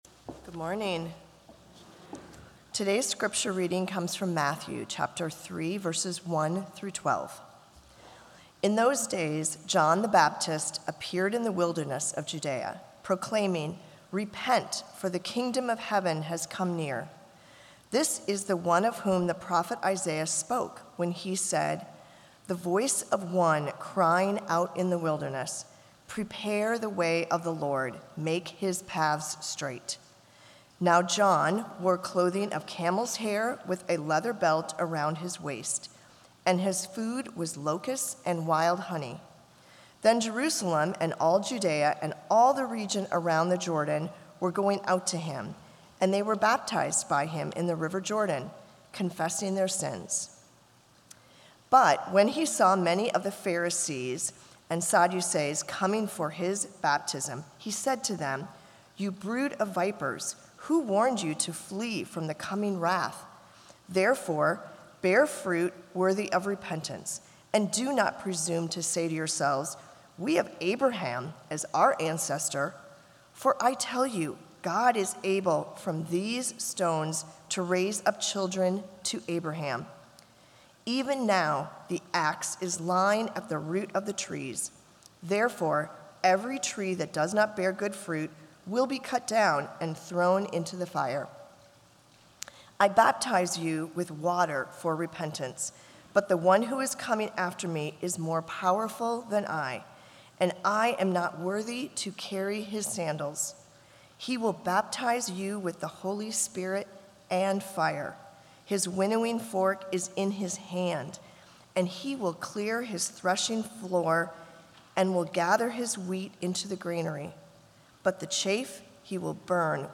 Cantata: Celebration of Love - Floris United Methodist Church